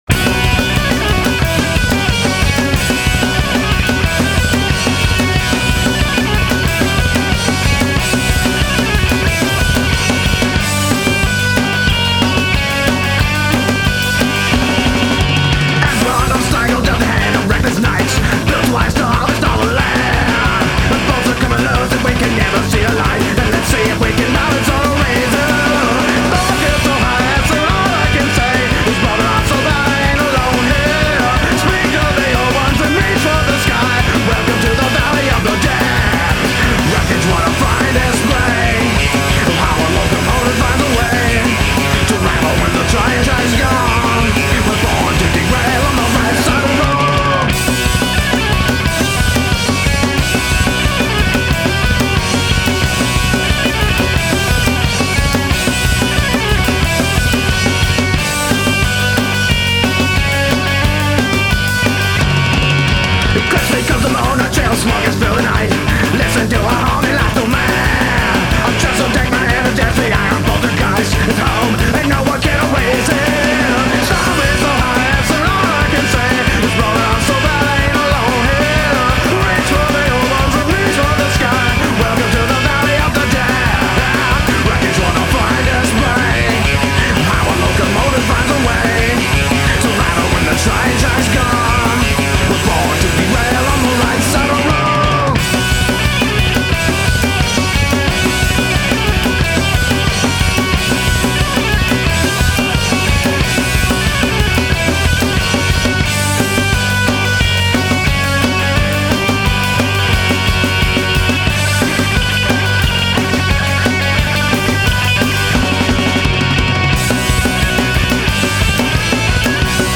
Classic Oi & Punk vs Psychobilly Punk'nRoll !